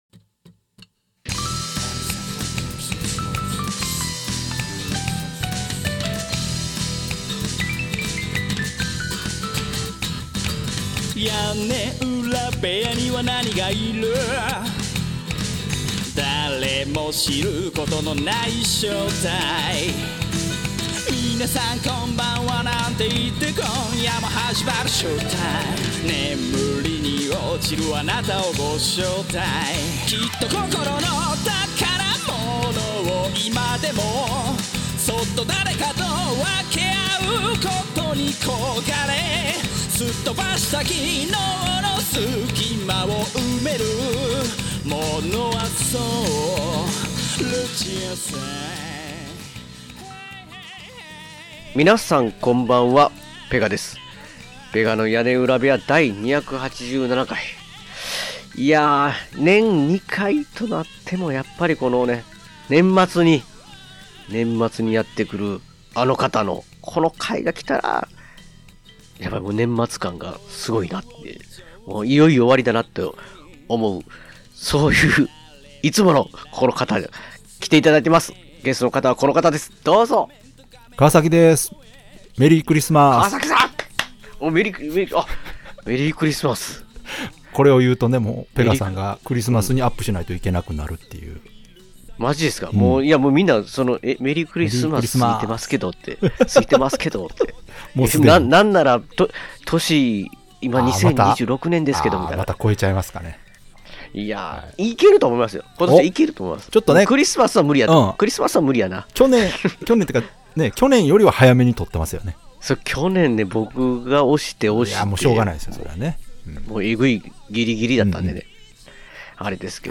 仲間とのきままなトークに耳をかたむけてください。